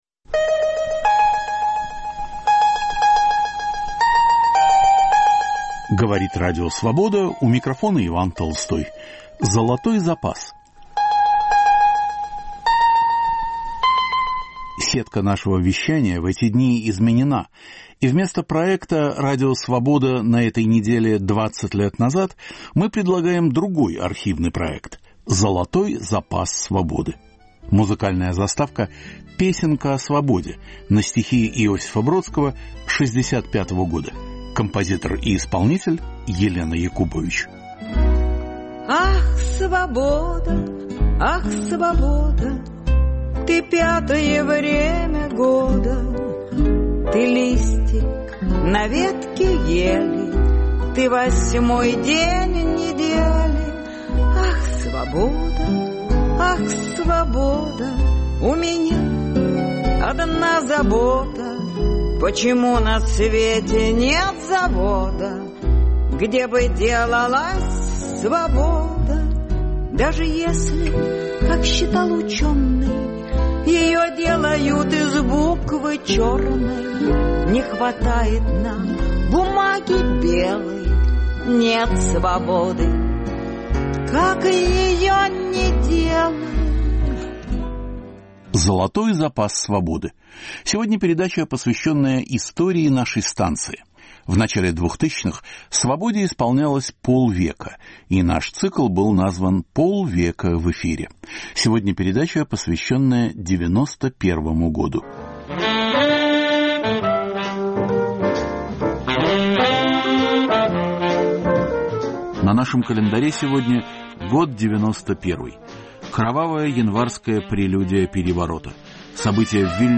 К 50-летию Радио Свобода. 1991, архивные передачи: события в Вильнюсе и Риге, финансовые шаги нового премьер-министра Павлова, путч в Москве, Конгресс соотечественников, роспуск КПСС, развал СССР, миф и правда о войне.